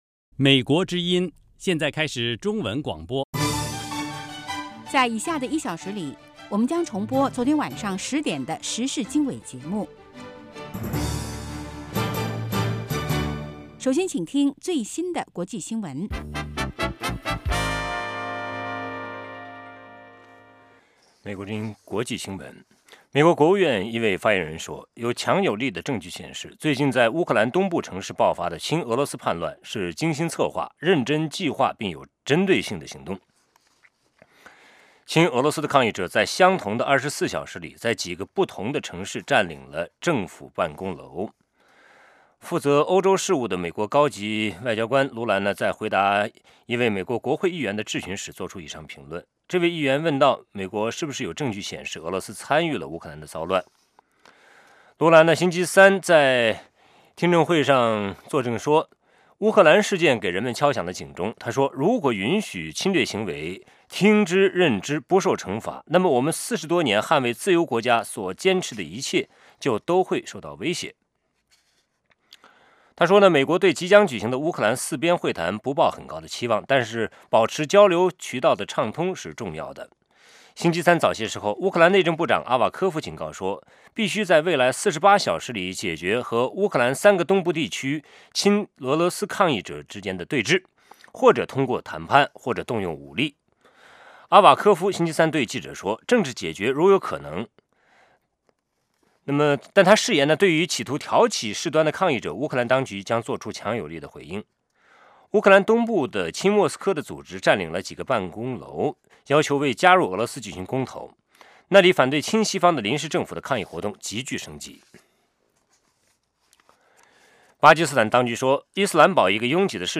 国际新闻 时事经纬(重播) 北京时间: 上午6点 格林威治标准时间: 2200 节目长度 : 60 收听: mp3